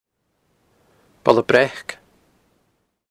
Placename around the Applecross peninsula
In other words, a svarabhakti vowel at the end of the first element of the name.